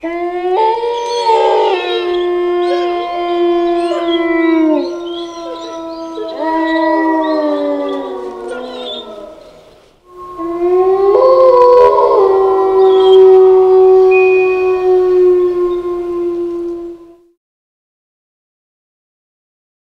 Werewolf Sound Effect Free Download